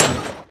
Minecraft Version Minecraft Version snapshot Latest Release | Latest Snapshot snapshot / assets / minecraft / sounds / mob / blaze / hit3.ogg Compare With Compare With Latest Release | Latest Snapshot